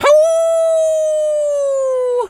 wolf_howl_03.wav